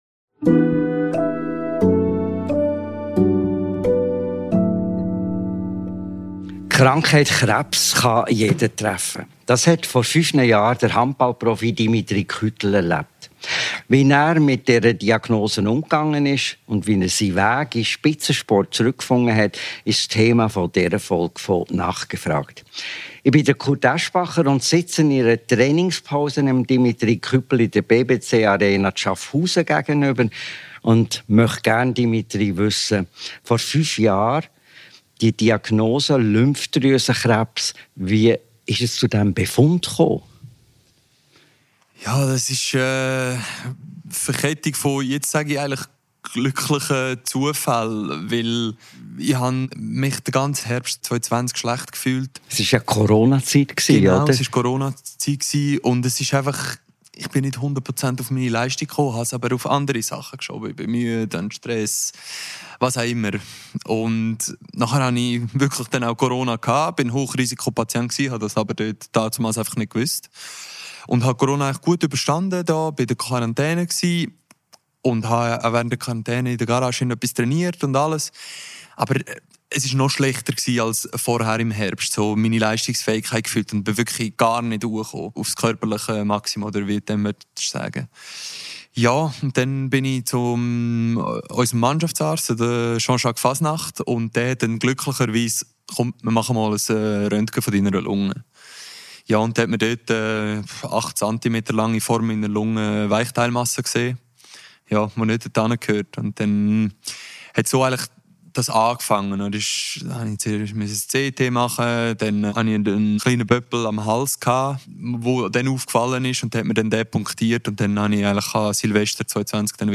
Ein Gespräch über den Bruch mit der eigenen Leistungsfähigkeit, den mentalen Kampf zurück ins Leben und in den Sport, über Dankbarkeit, neue Prioritäten und darüber, weshalb Krebs kein Tabuthema sein sollte.